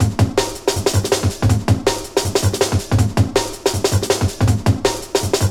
Thunder.wav